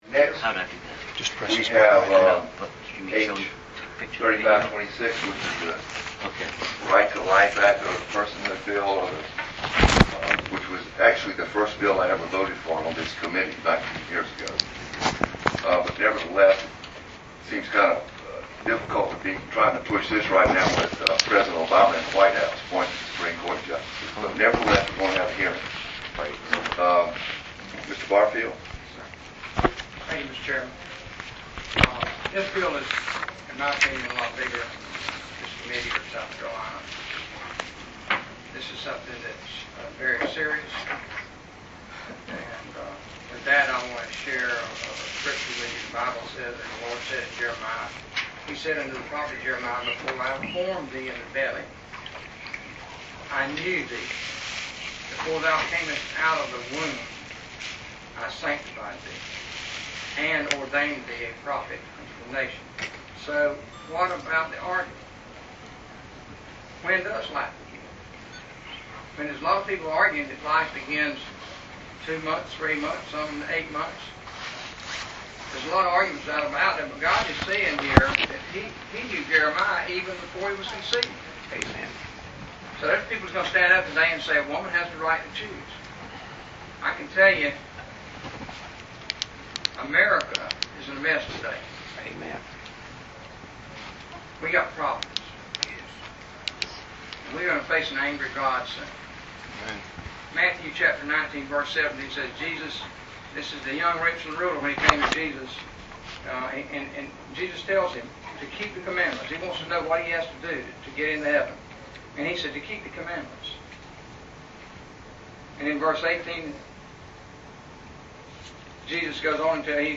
- Recall motion by Senator Lee Bright (R-Spartanburg); Rant in opposition by Senator Glenn McConnell (R-Charleston) Part 3 (19:28 min.)
- Final Tabling motion vote tally , 24 - 18 in favor of tabling (killing) Personhood Bill Recall motion ; Senate Adjourns (Note : Christian Pro-Life Senator Larry Grooms voted "Nay" to motion to Table )